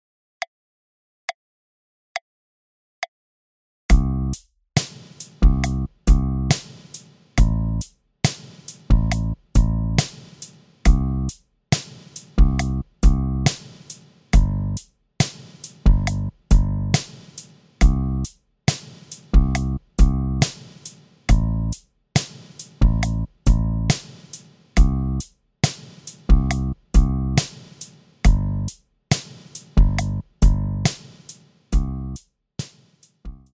Programmiertes Zeug
dfBasDrum.mp3